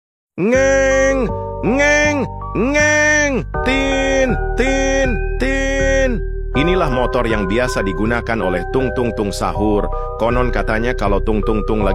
storung tung sahur Meme Sound Effect